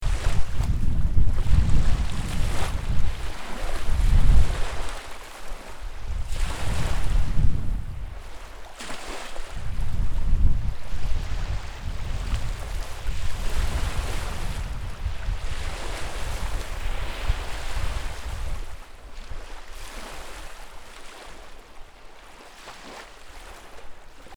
Здесь вы найдете успокаивающие шум волн, грохот прибоя и шелест прибрежного песка.
Шум волн и ветра на речном пляже у моря